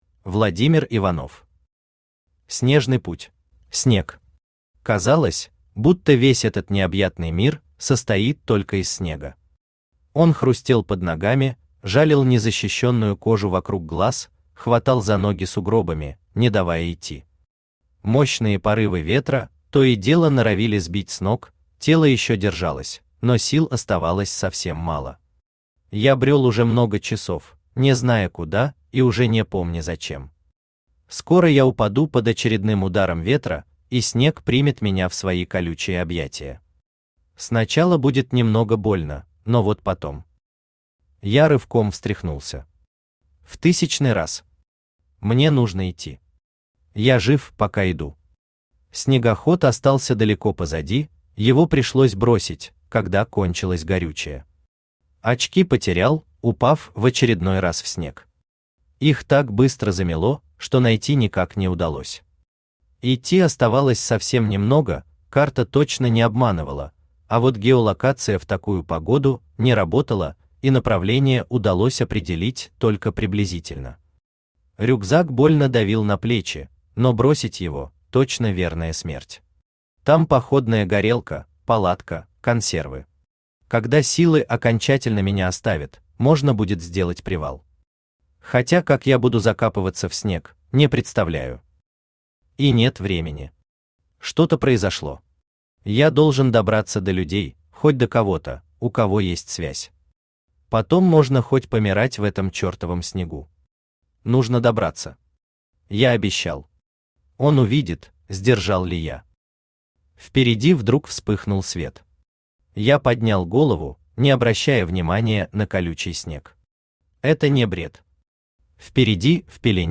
Aудиокнига Снежный путь Автор Владимир Иванов Читает аудиокнигу Авточтец ЛитРес.